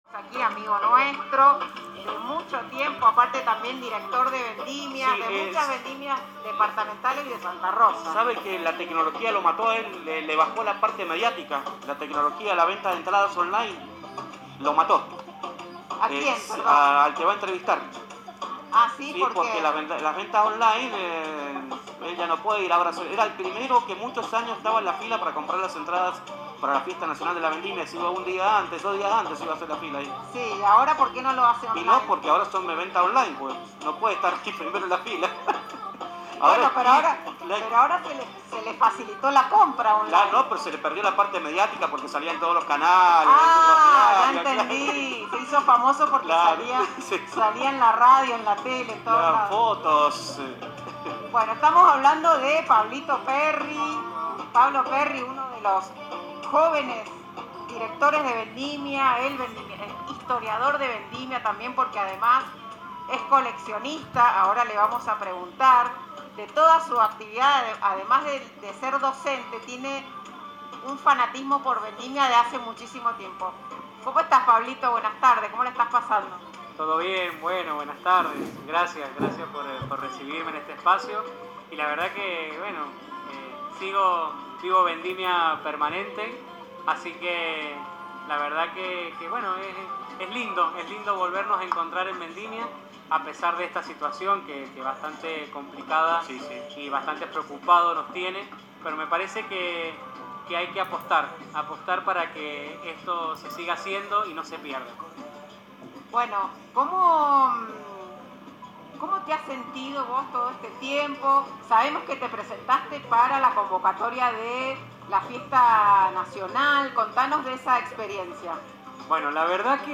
Conocelo más a fondo, en una entrevista, recientemente descubierta por el equipo de Radio Vendimia, que concedió en la antesala de la fiesta departamental de Santa Rosa del año 2022. Conocerás mas acerca de su recorrido y sus comienzos.